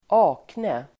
Uttal: [²'ak:ne]